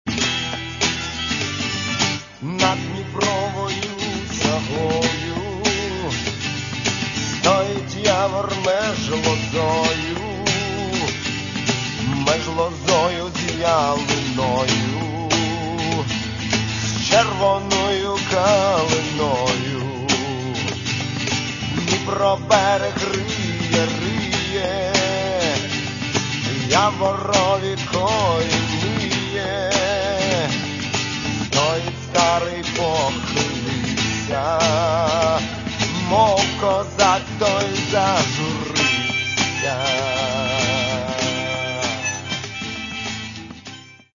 Catalogue -> Rock & Alternative -> Poetic Rock